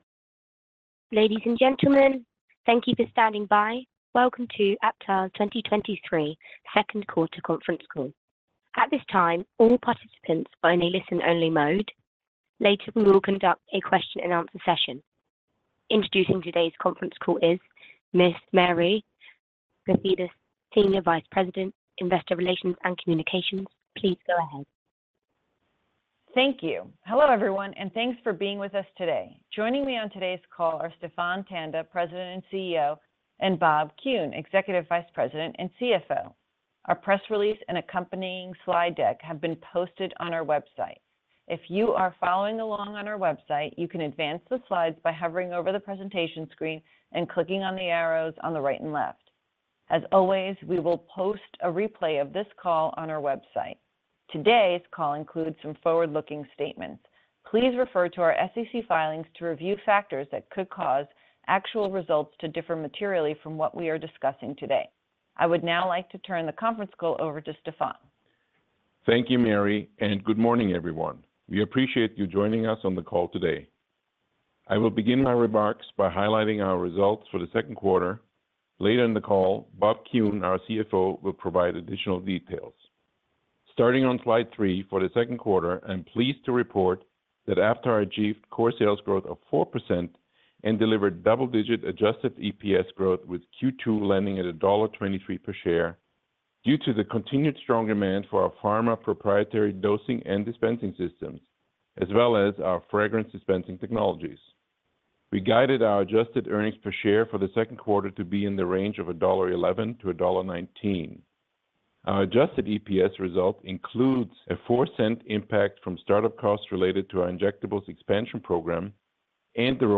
Q2 2023 Results Earnings Conference Call MP3 (opens in new window)